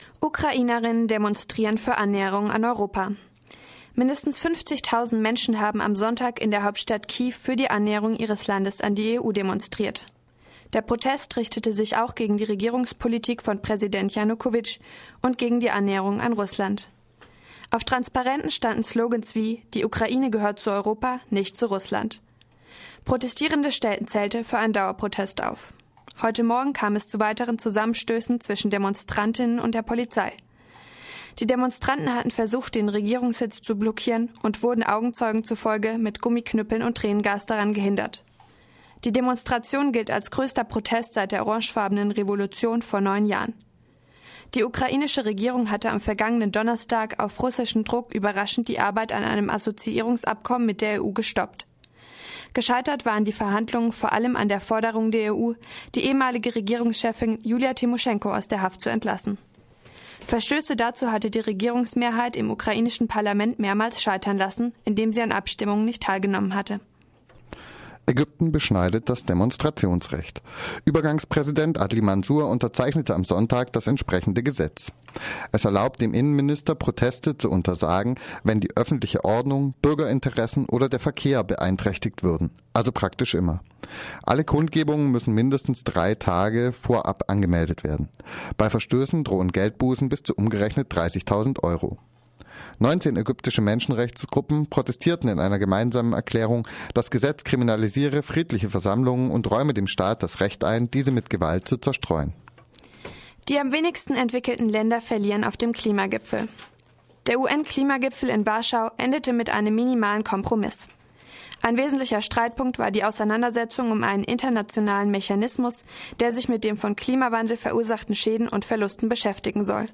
Focus Europa Nachrichten vom Montag, den 25. November - 12.30 Uhr